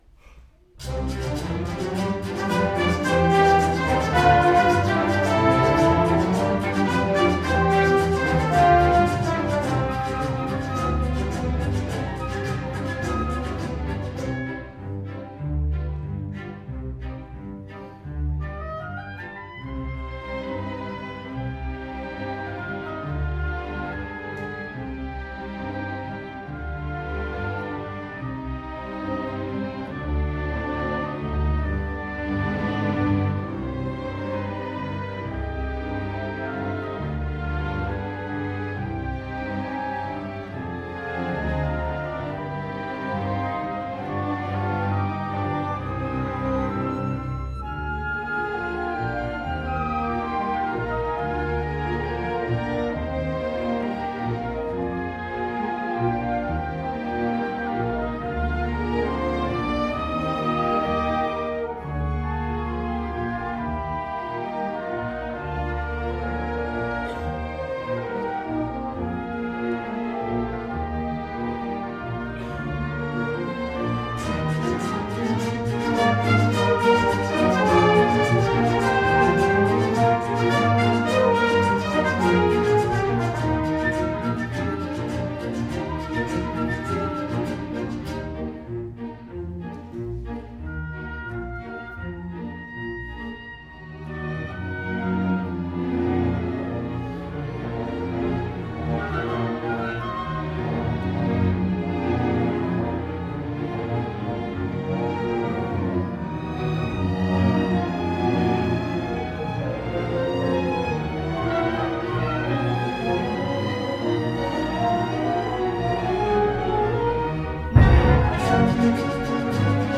Orchestre symphonique du CRR 93 (2018)